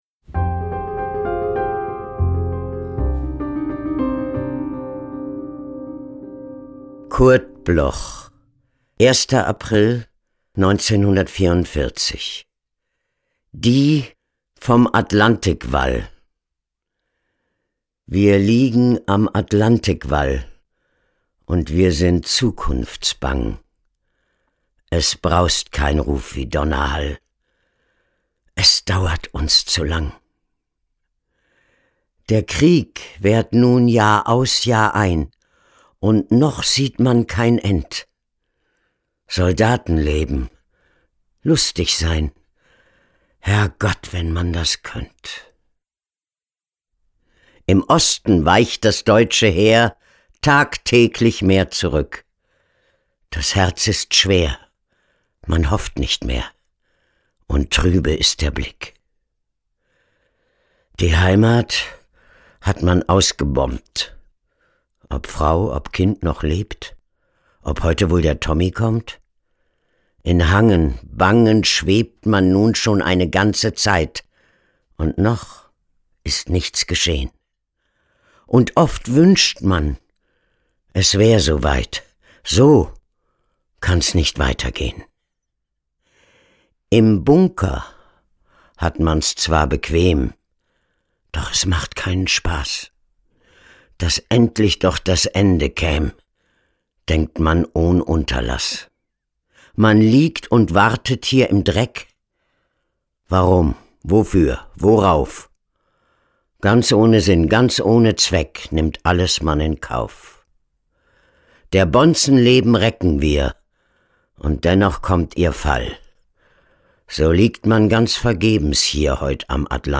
Mechthild Großmann (* 1948) is een Duitse actrice en spreekster.
Recording: Killer Wave Studios, Hamburg · Editing: Kristen & Schmidt, Wiesbaden
Mechthild-Grossmann-Die-vom-Atlantikwall-mit-Musik_raw-1.m4a